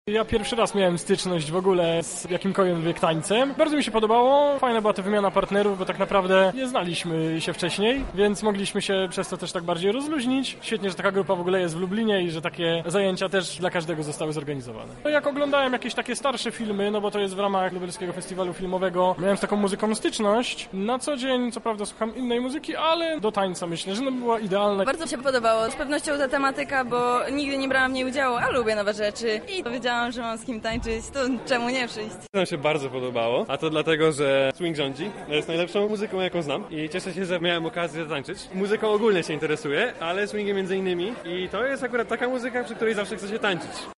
Nasz reporter zapytał uczestników o wrażenia podczas próby.